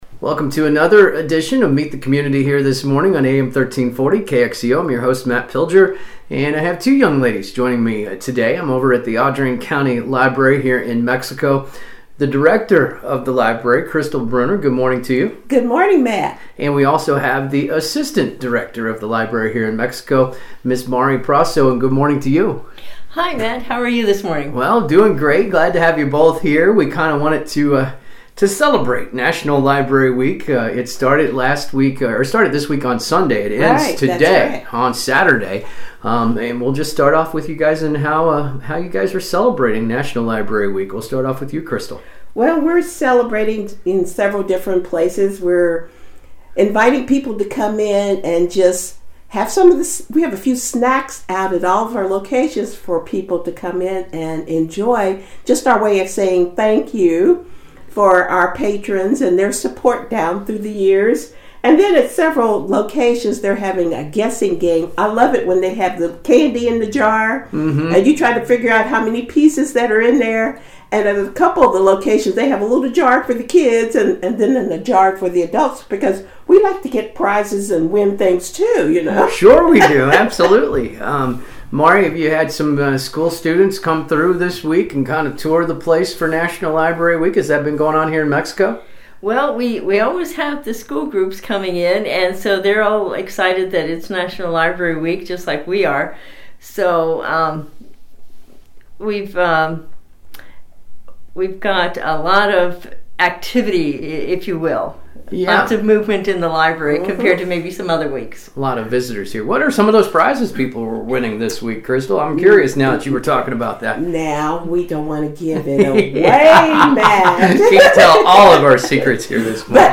Local Talk Show Meet The Community